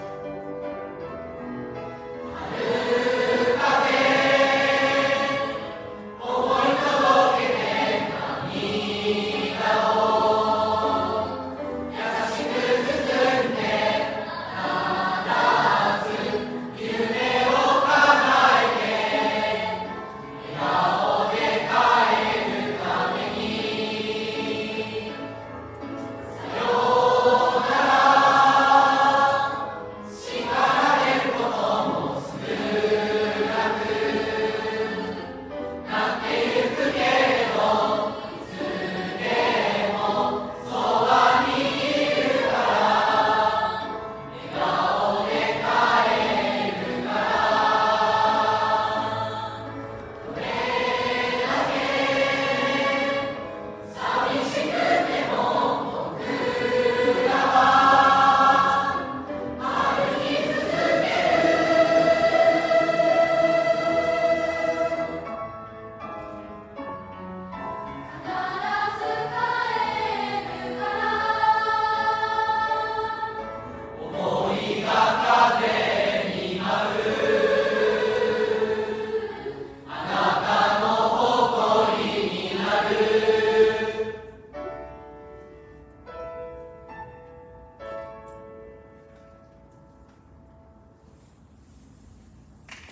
47期生 3年生合唱コンクール
また、最後の学年合唱では、3年生らしいダイナミックでそして心に伝わる歌声でした。
学年合唱 「遥か」 3年生学年合唱後半 ⇐合唱の一部が聞けます。